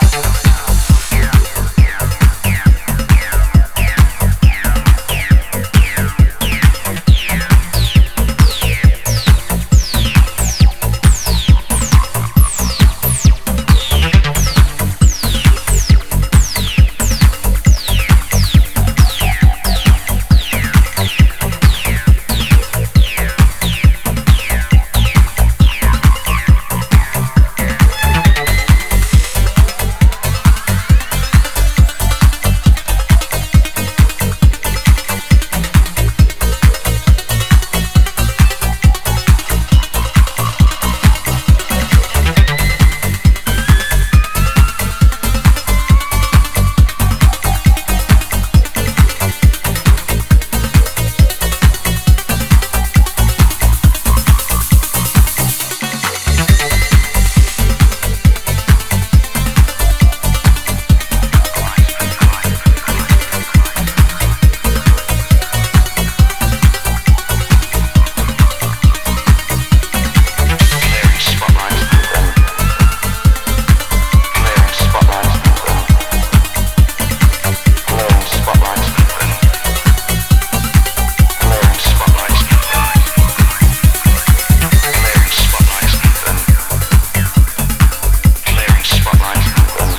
中盤から登場する捩れたアラビック音階のリードと強靭なビートでぐんぐん上昇する